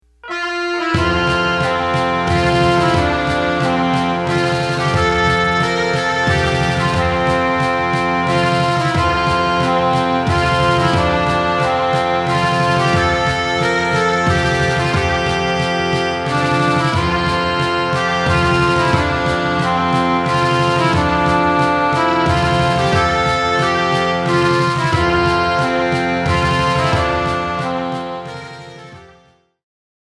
Scottish Folk Single.